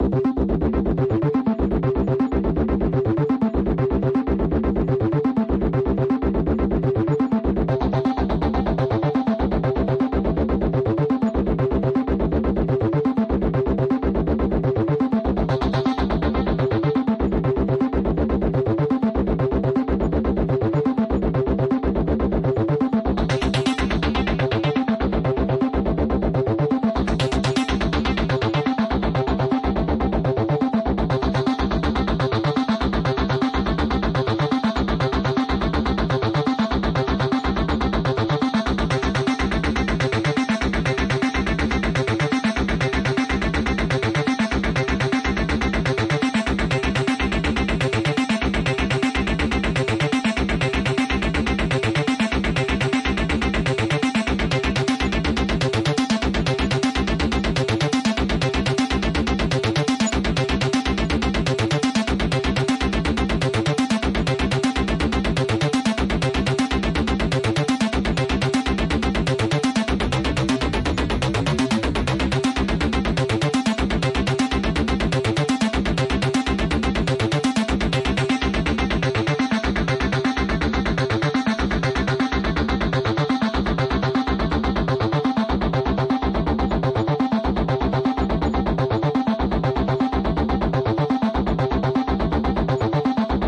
描述：使用ableton仪器模拟磁带延迟创建的另一个acis合成器
Tag: 回路 ABLETON活 舞蹈 合成器中 与软件合成器 125 TECHNO BPM ABLETON 合成器 合成